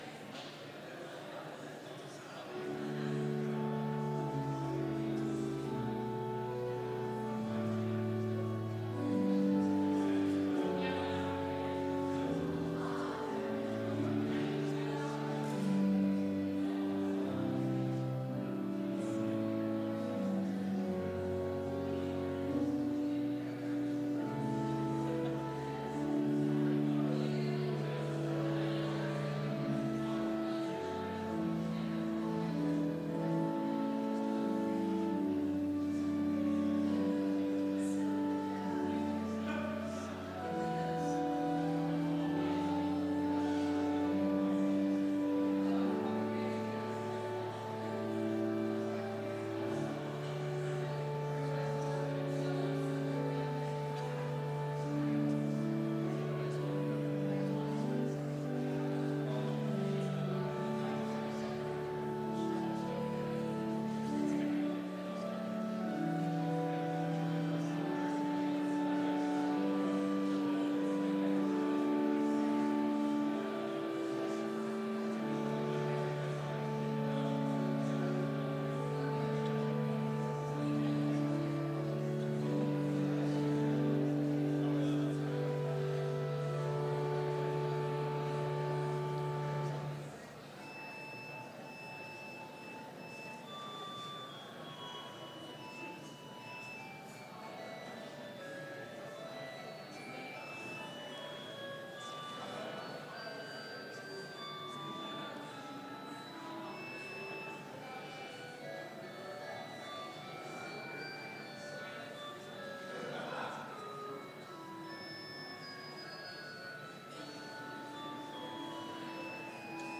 Complete service audio for Chapel - October 9, 2019